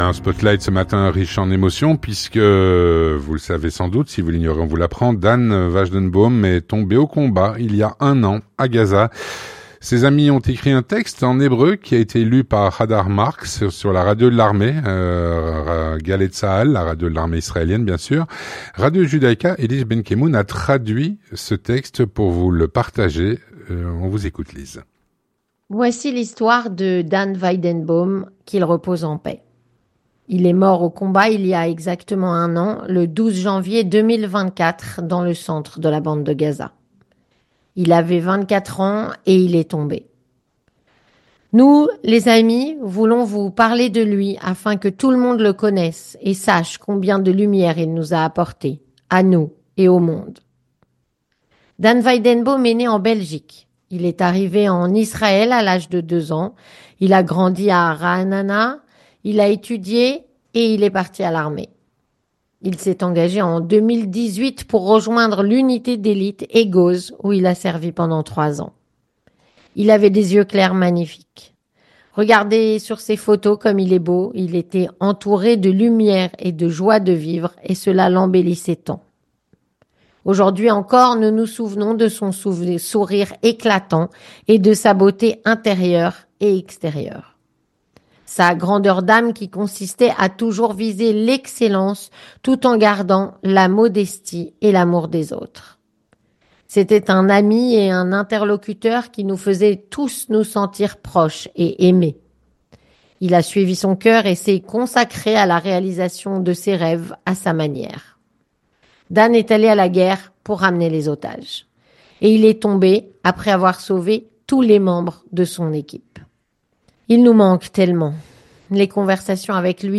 Texte en français lu